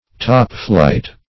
Topflight \Top"flight\, a.